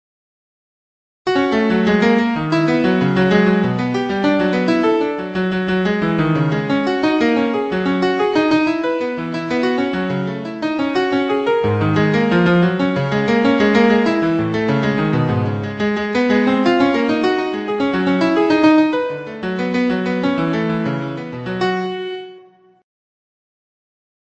(The timing and dynamics are composed by the program and played by a MIDI player.)